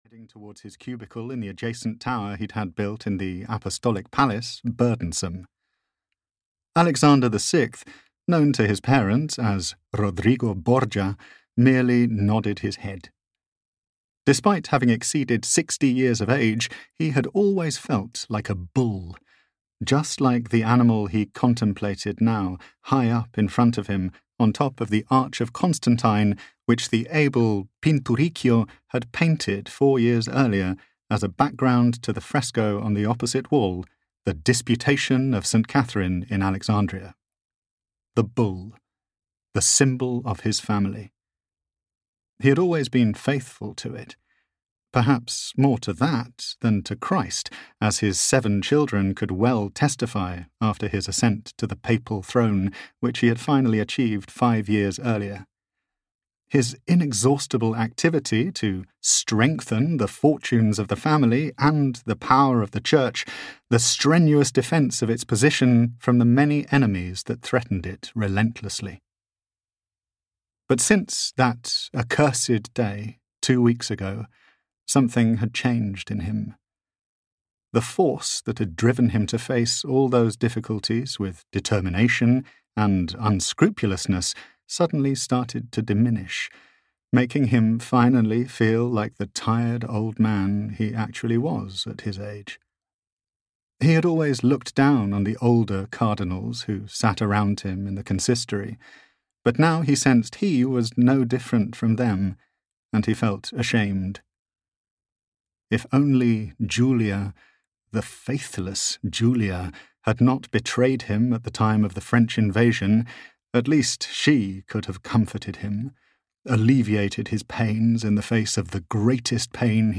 The Borgias' Spy (EN) audiokniha
Ukázka z knihy